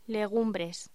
Locución: Legumbres
voz